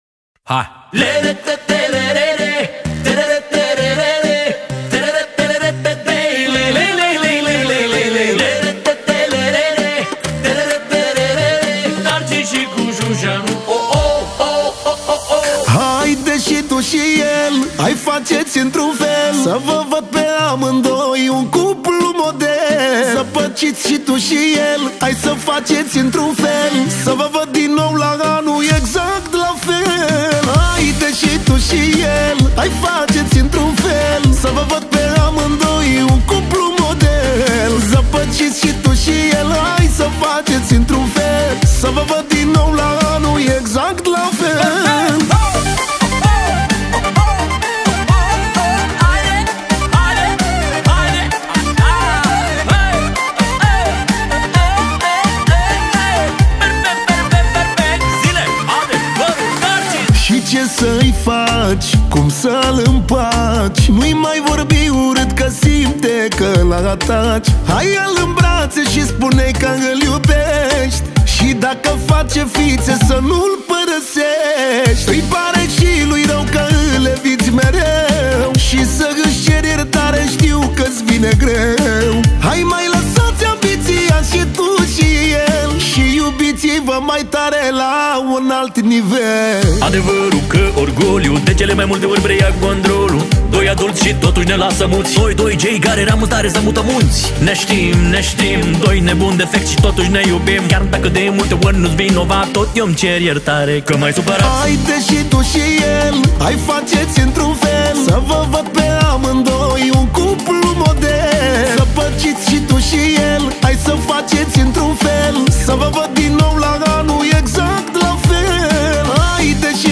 Muzica Usoara